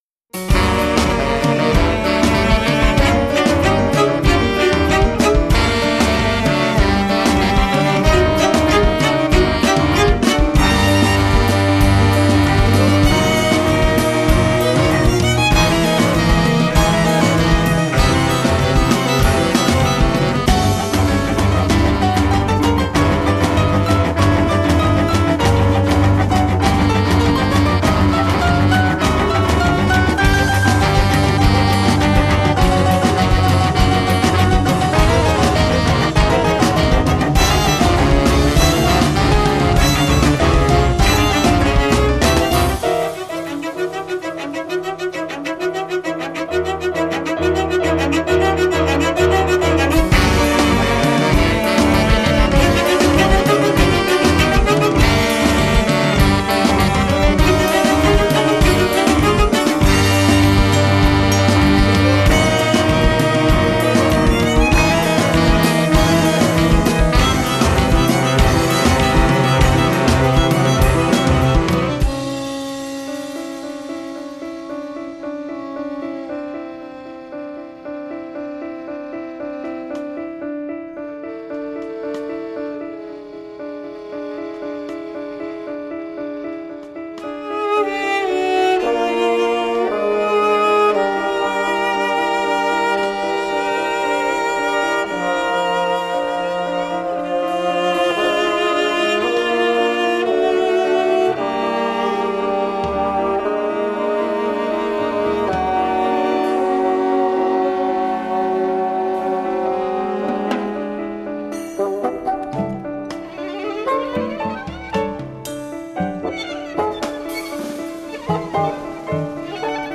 chamber music, rock, theatre and improvisation
Violin, cello, bassoon, saxes and accordion